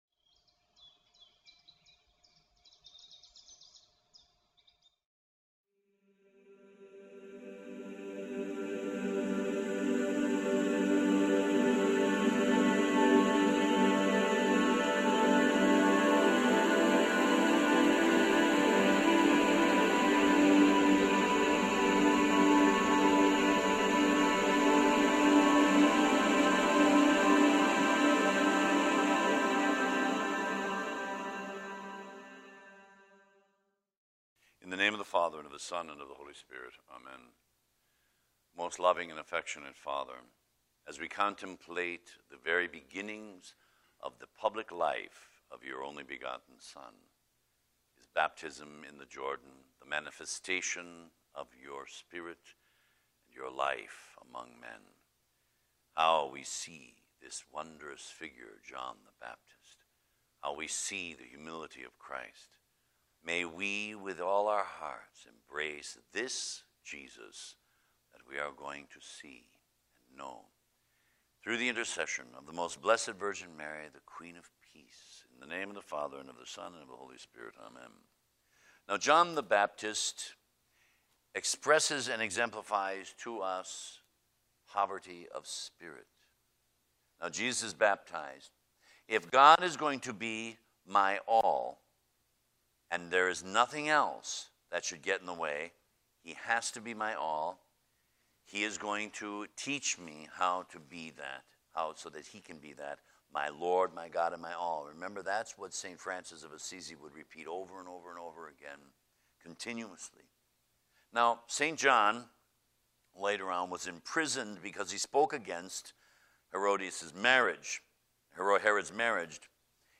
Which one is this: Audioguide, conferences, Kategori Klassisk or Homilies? conferences